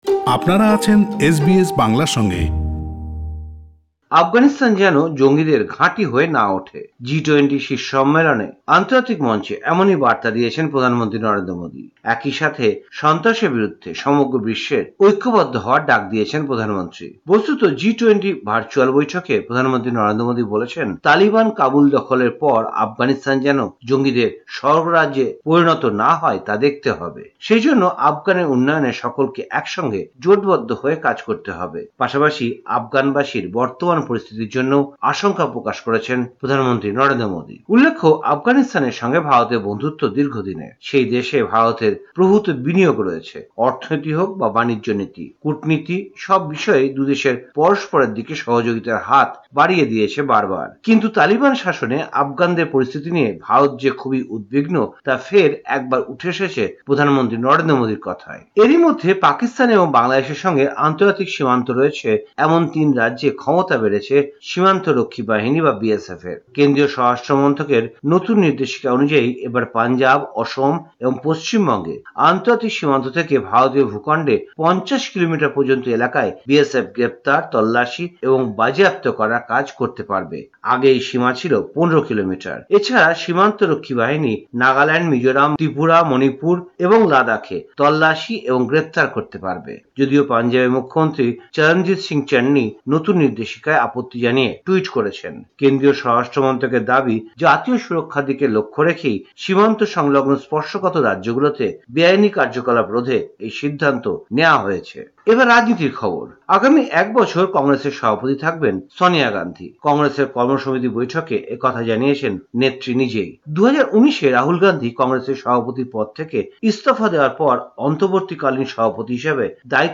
ভারতীয় সংবাদ: ১৮ অক্টোবর ২০২১
কলকাতা থেকে